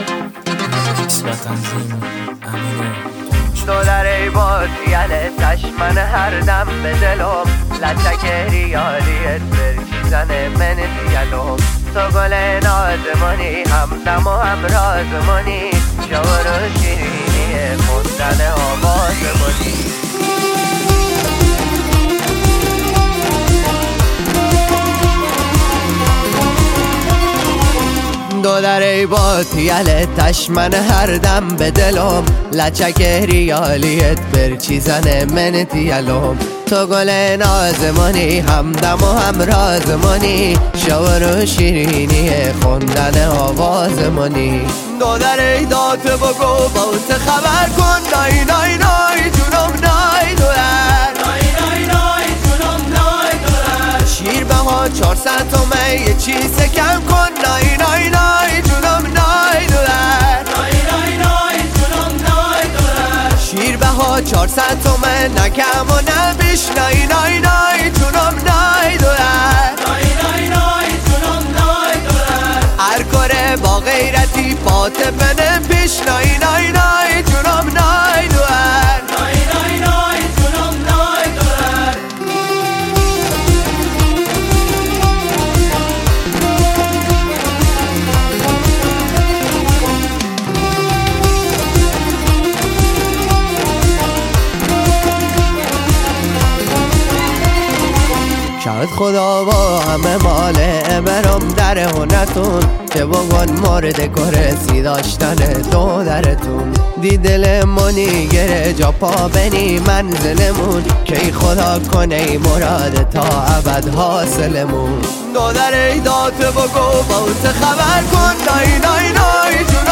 اهنگ شاد بختیاری شاد لری